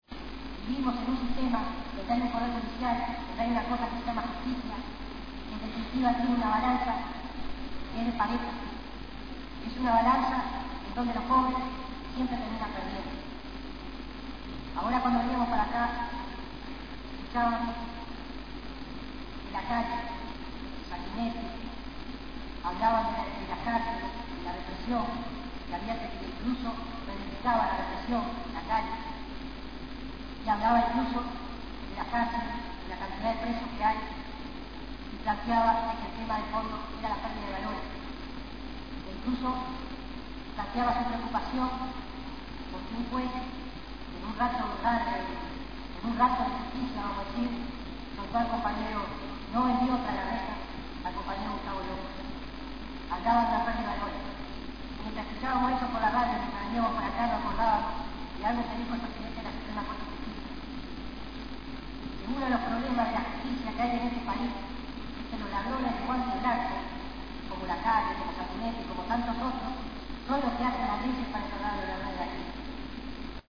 A continuación extractos grabados por Indymedia/Uruguay de las palabras dichas por